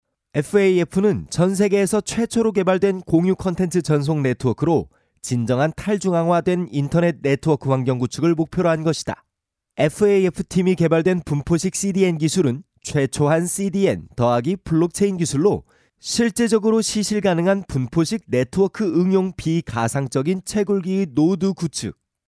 擅长：专题片 广告
特点：大气浑厚 稳重磁性 激情力度 成熟厚重
风格:浑厚配音